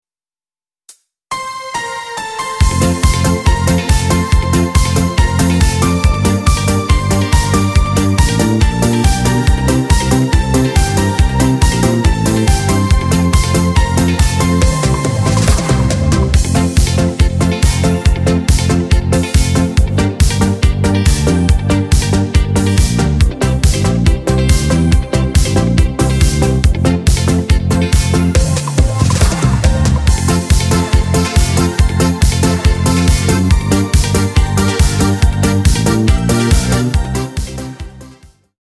odświeżona wersja aranżacyjna
Disco Polo